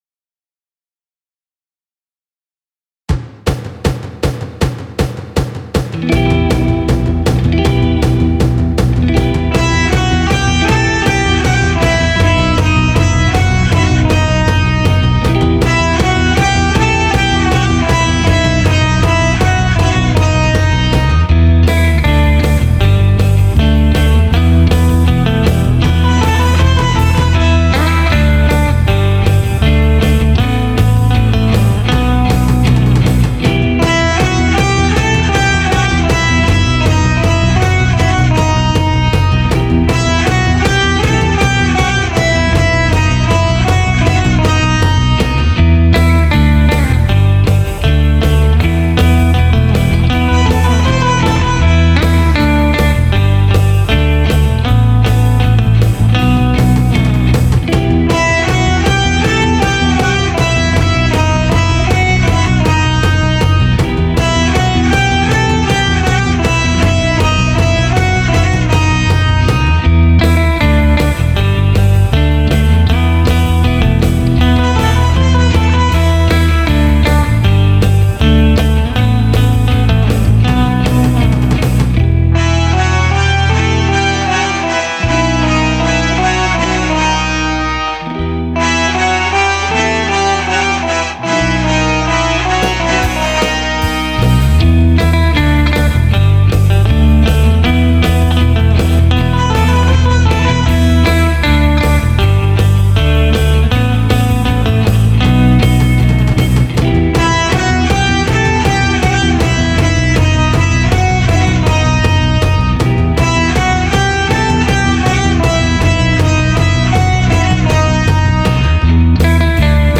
Testing a Electro Harmonix Ravish Sitar pedal
Black Jazzmaster - Doing all the guitar work